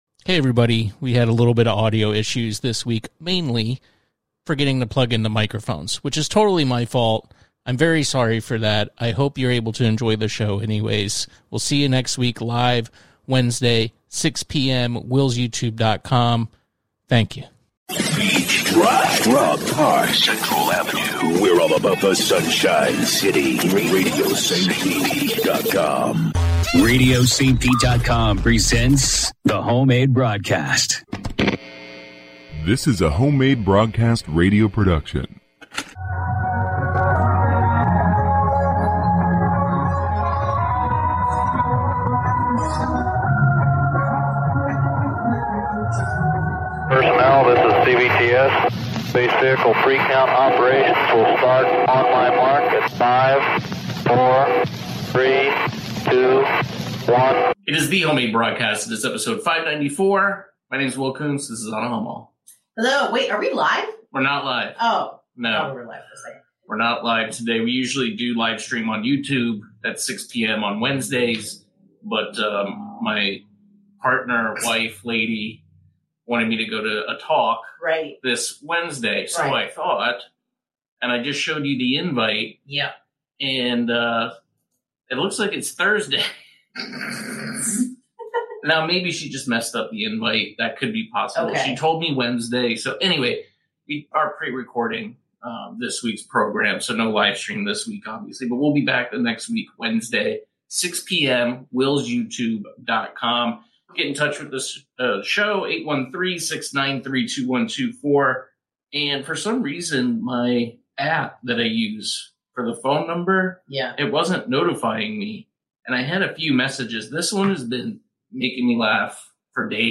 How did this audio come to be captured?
***WE HAD SOME MICROPHONE ISSUES, SO SORRY FOR THE SOUND QUALITY***